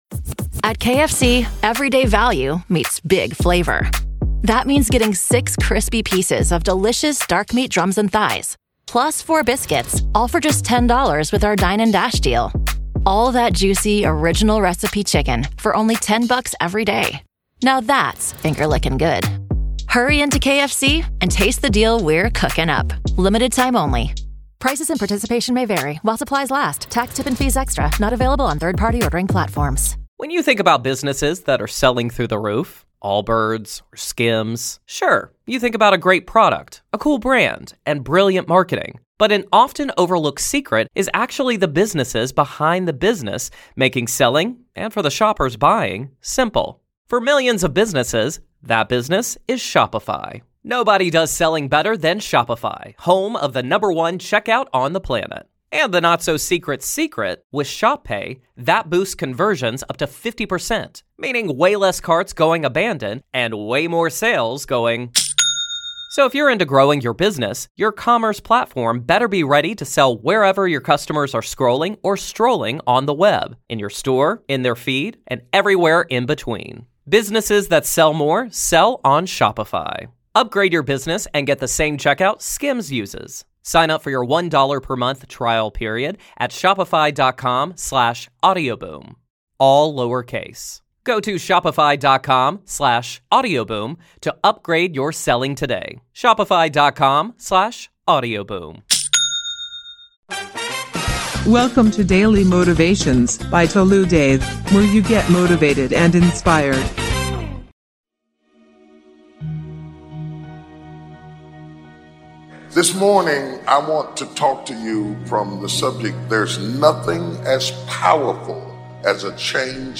Speakers: Chris Oyakhilome TD jake Pastor Creflo Dollar